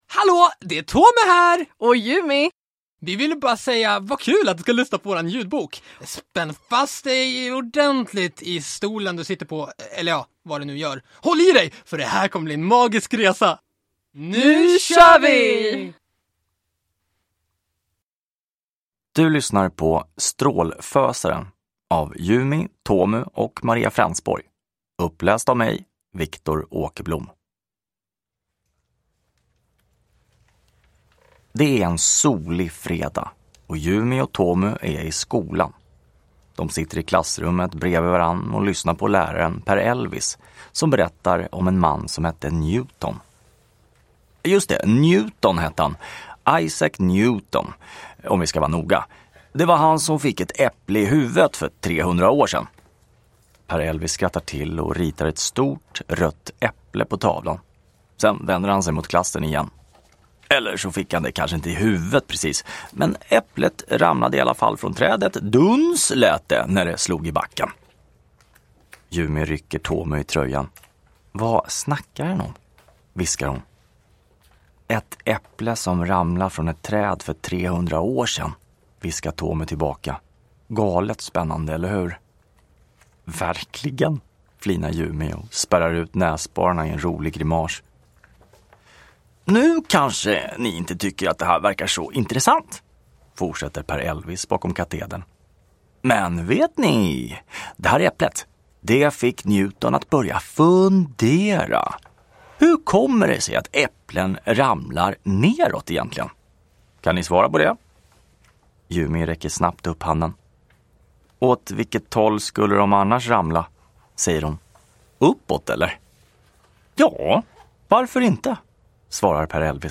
Strål-fösaren – Ljudbok